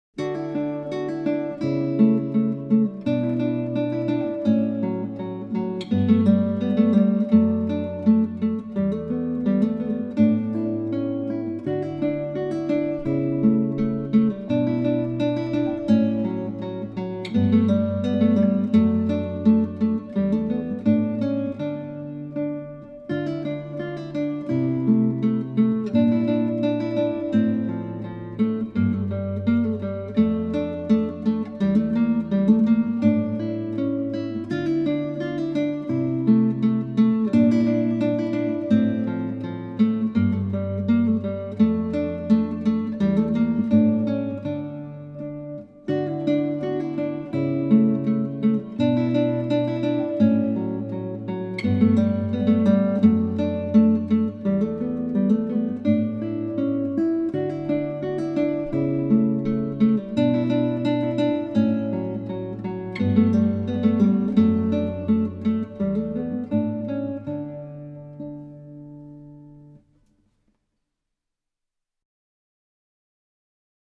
Gitarre Solo
• Instrumenten: Gitarre Solo